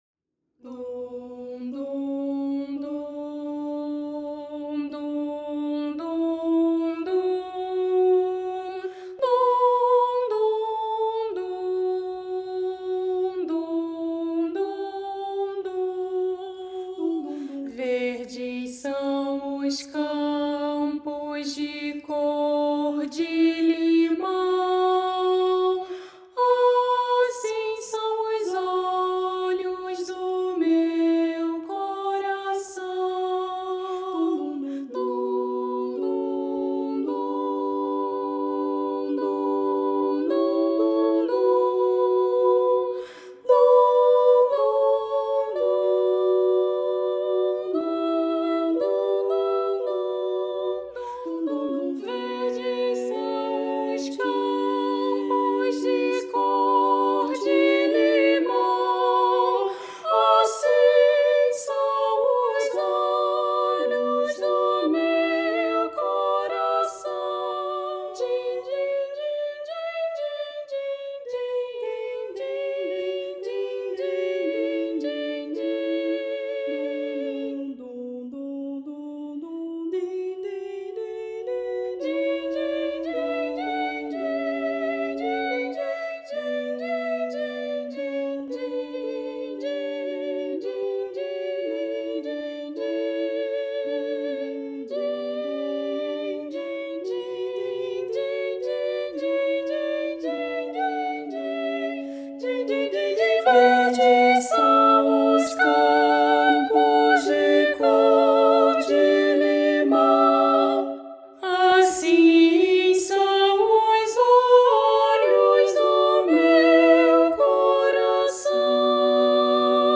para coro infantil a três vozes